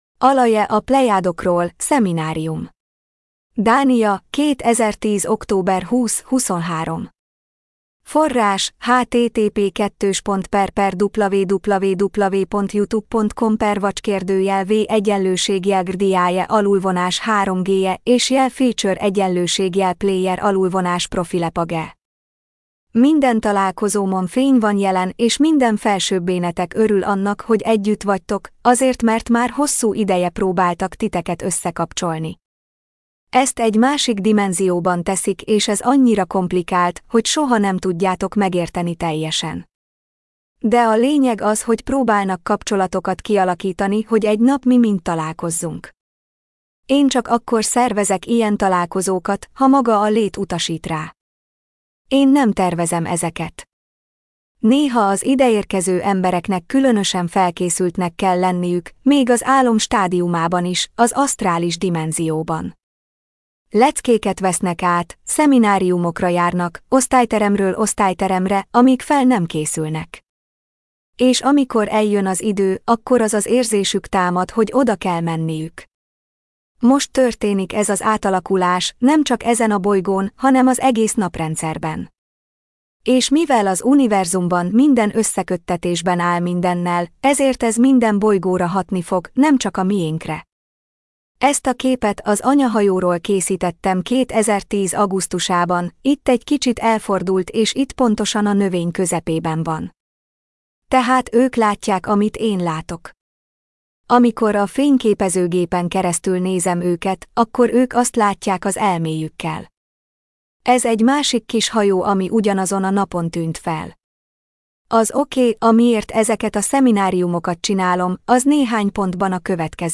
MP3 gépi felolvasás